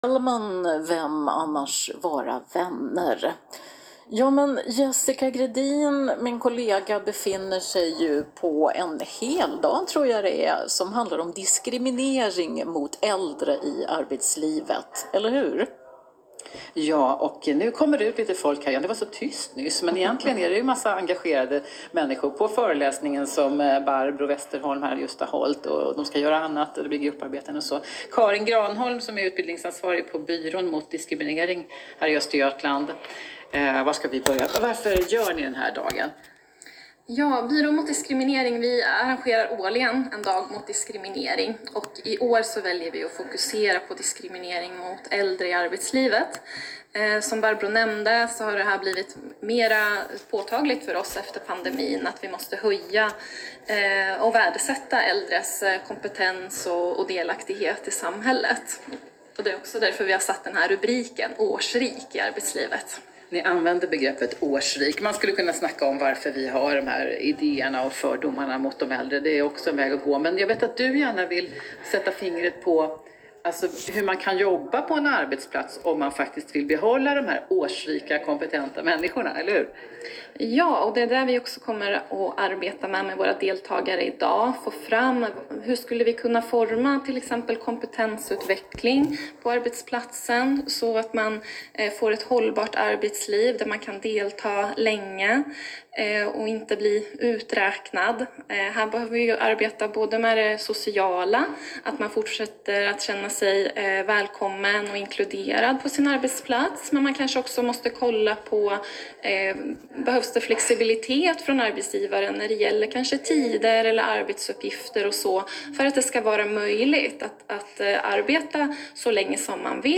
Intervju med P4 Östergötland om Dagen mot diskriminering med tema ”Årsrik i arbetslivet”